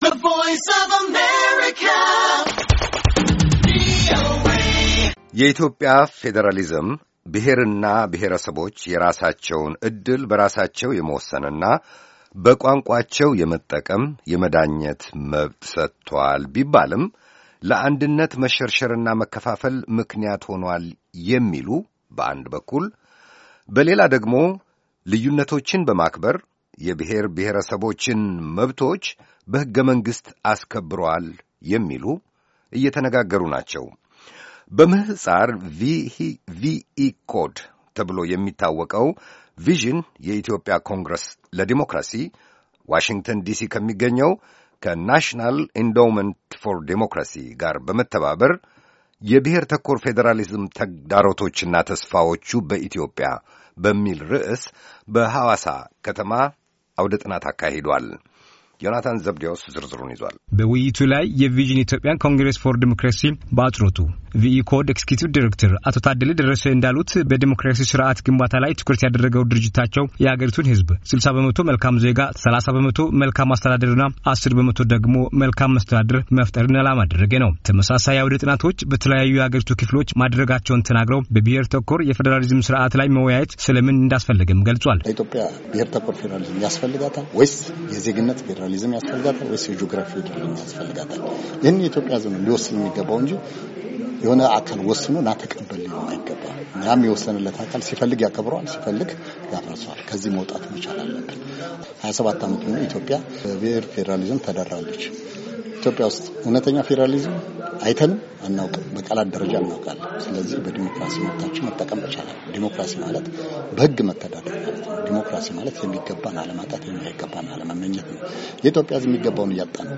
ውይይት - በ «ብሄር ተኮር ፌዴራሊዝም»